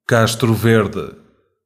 Castro Verde (European Portuguese pronunciation: [ˈkaʃtɾu ˈveɾðɨ]